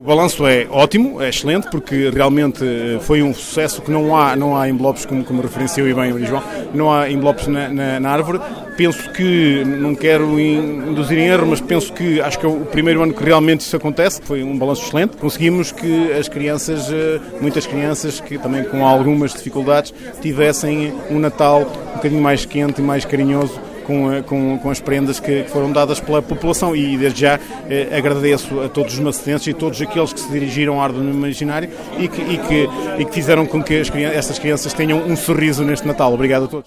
O presidente da Câmara, Sérgio Borges, destacou a forte adesão da comunidade e o espírito solidário demonstrado: